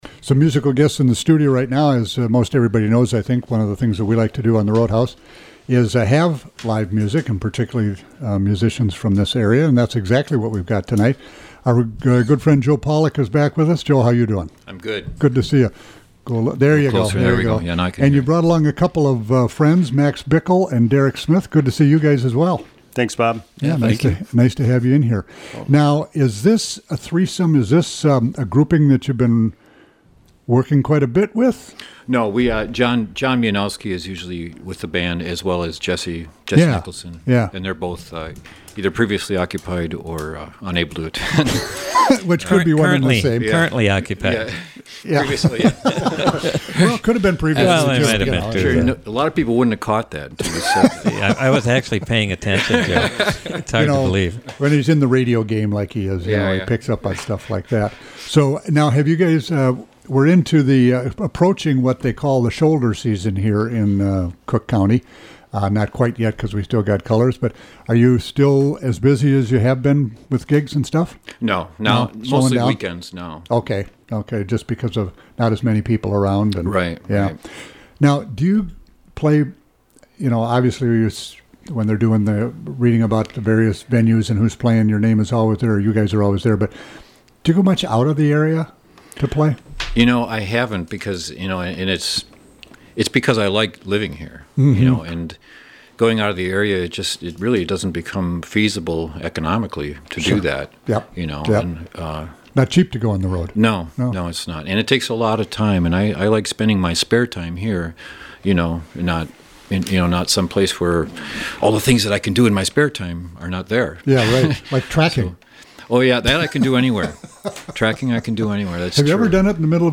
guitar, vocals
fiddle
percussion) for live music in Studio A Oct. 4.